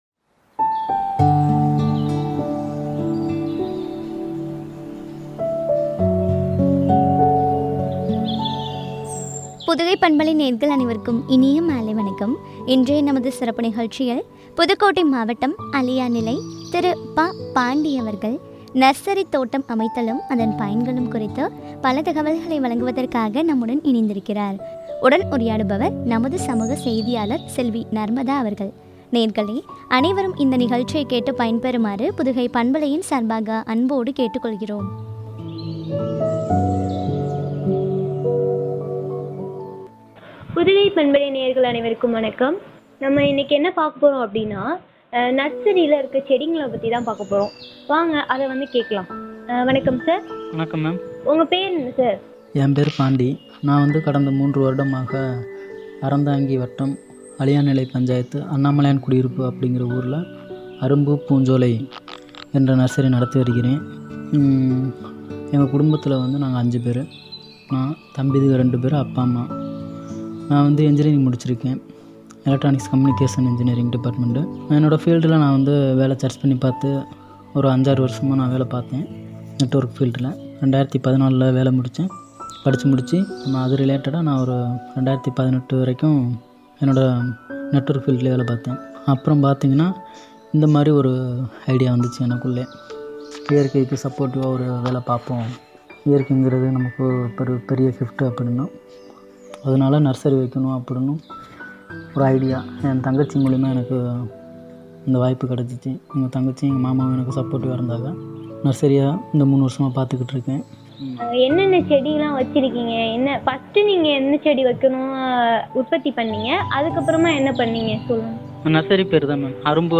பயன்களும் பற்றிய உரையாடல்.